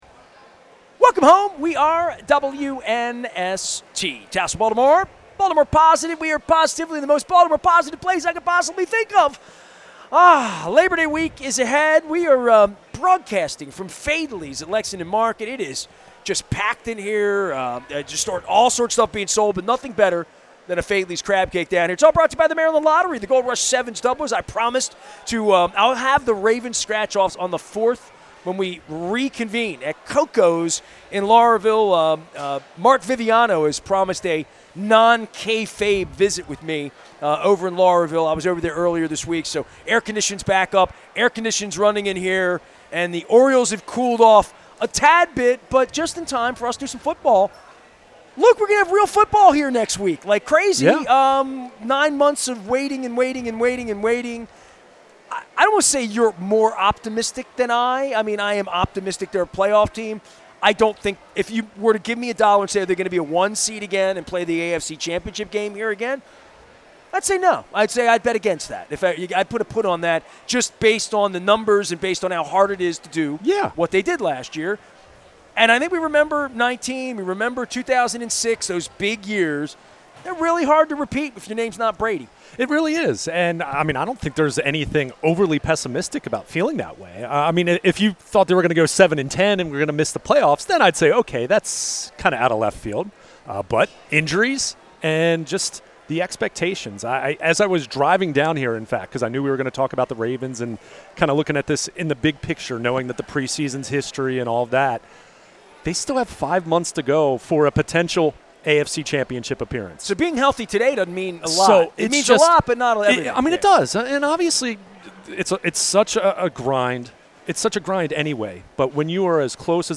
from Faidley's on the Maryland Crab Cake Tour